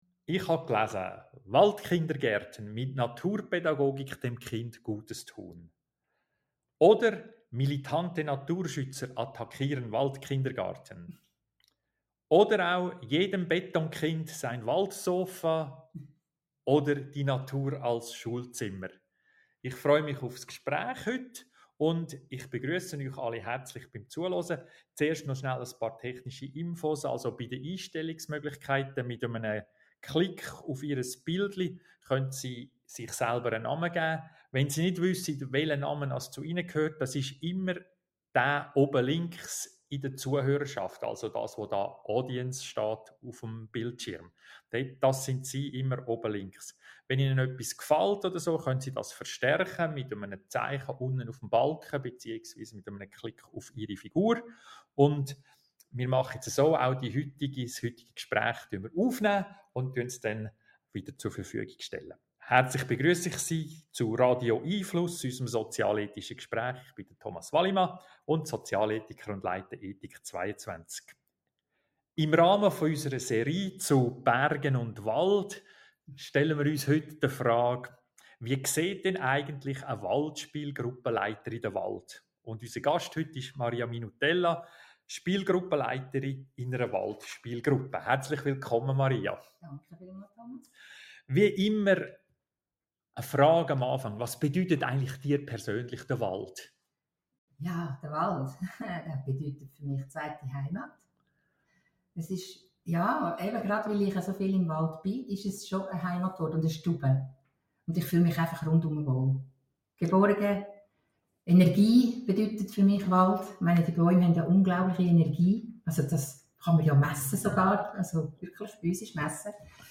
Radio 🎙 einFluss findet jeden Mittwochabend von 18.30 - 19 Uhr statt. Live mitdiskutieren oder anonym zuhören - wir freuen uns auf Sie!